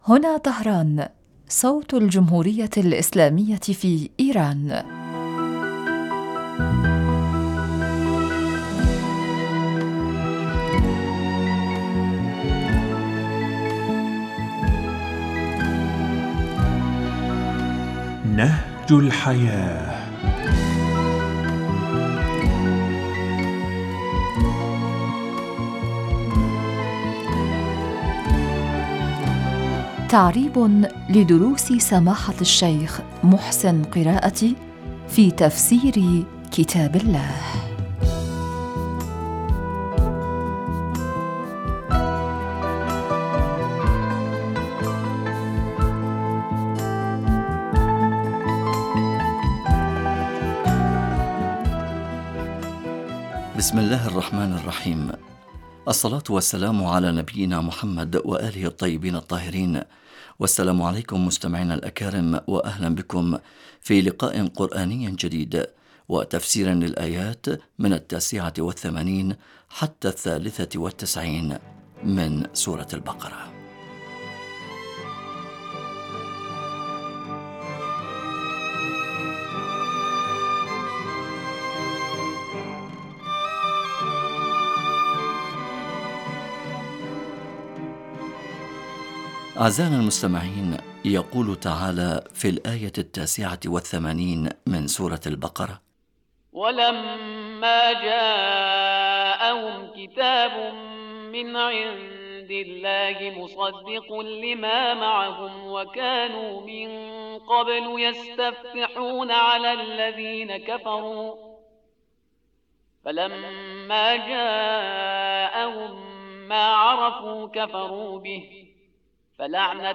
مستمعينا الكرام ما زلتم تستمعون الى برنامج نهج الحياة يقدم لحضراتكم من اذاعة طهران صوت الجمهورية الاسلامية في ايران.